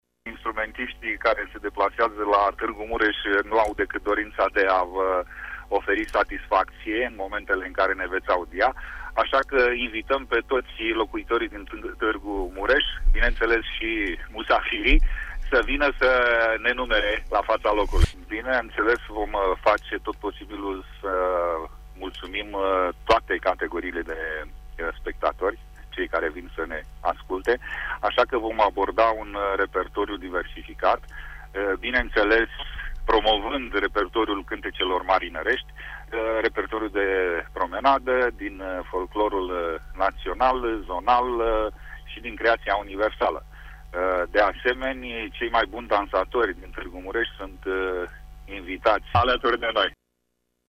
a precizat în emisiunea Sens Unic de la RTM